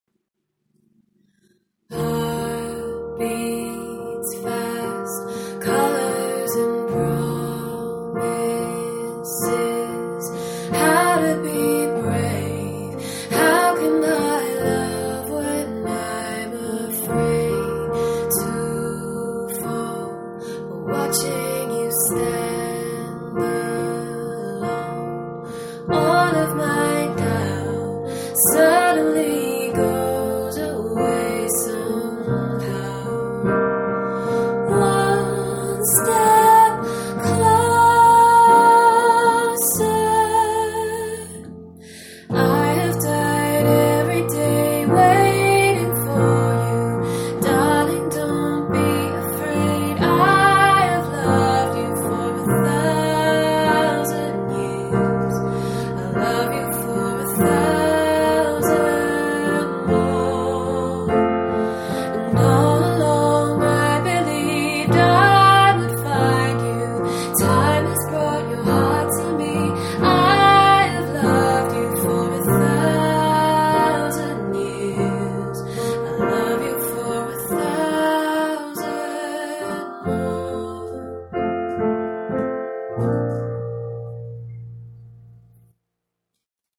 high-quality piano and vocal renditions
Solo Female Singer / Pianist in Yorkshire
smooth and soulful voice